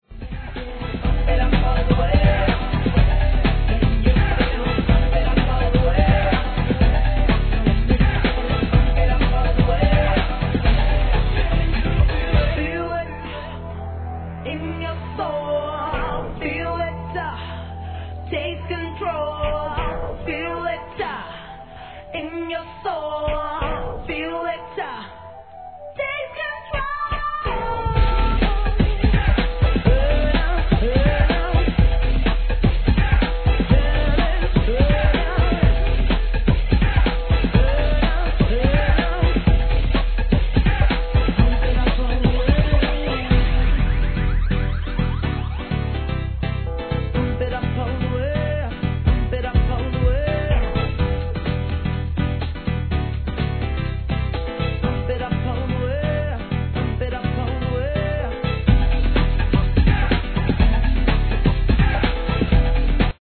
HIP HOP/R&B
跳ねたBEATにRAPを乗せるダンス・ナンバー!